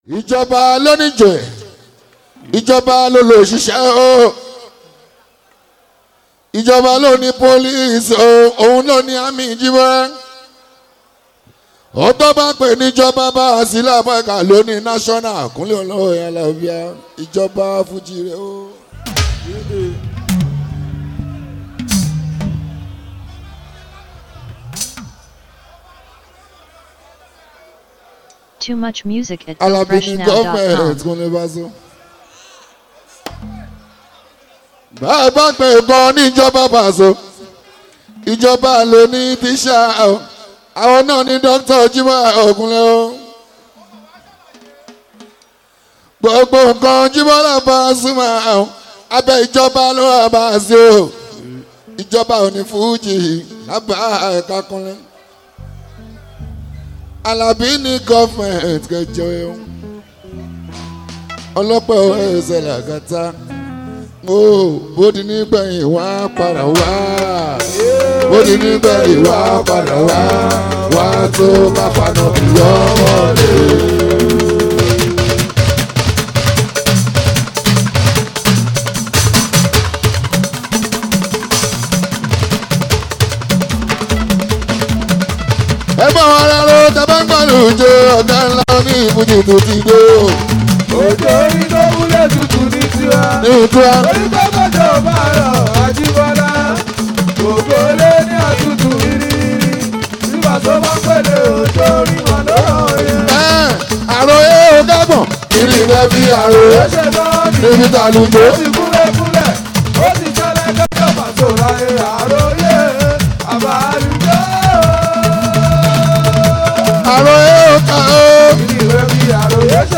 Latest Yoruba Fuji Music Mp3 below